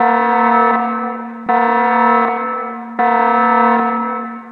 alarm_citizen_loop1_udata.wav